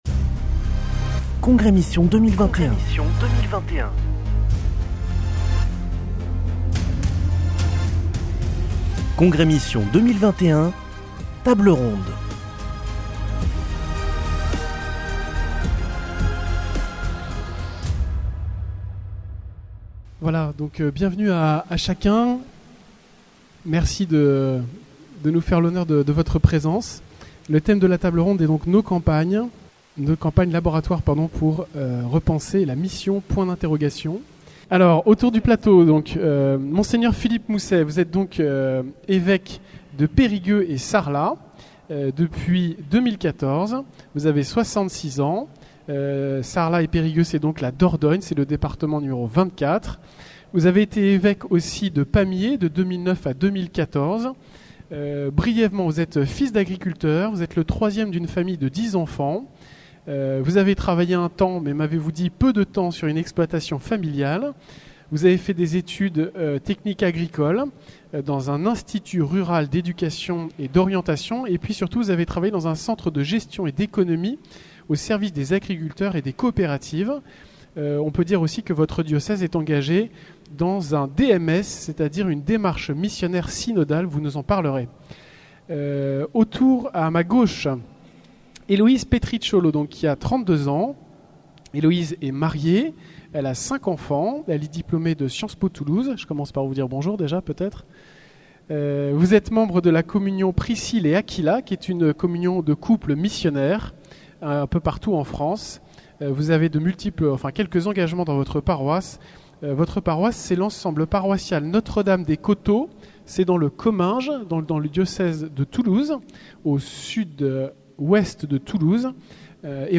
Table ronde. Nos campagnes : laboratoires pour repenser la mission.